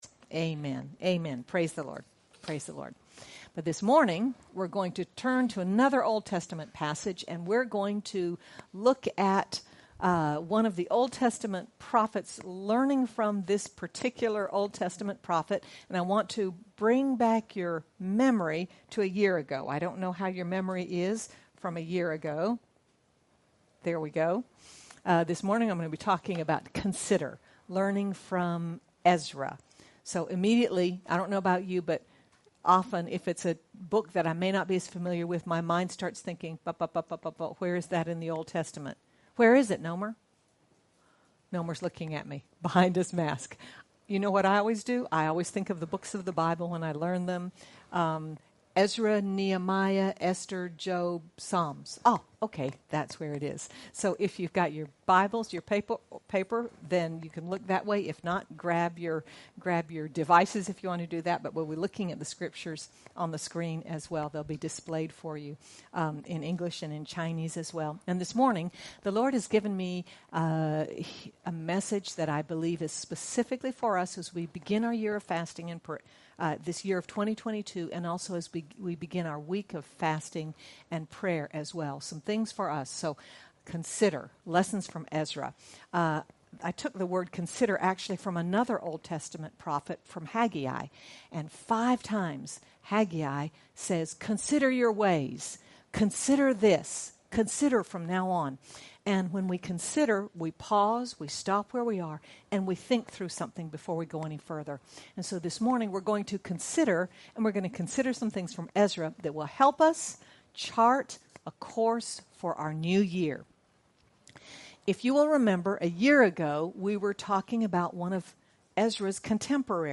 Whatever we face, our one sure foundation is that God Himself, personally, is with us. Sermon